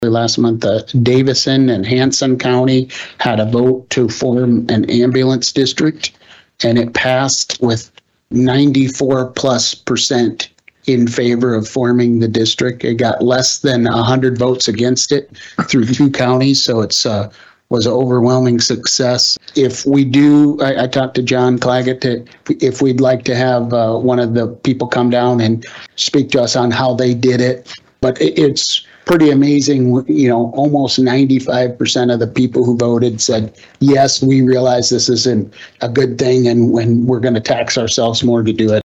County Commissioner, Dan Klimisch, brought up the idea at Tuesday’s meeting, citing the joint Davison-Hanson ambulance district.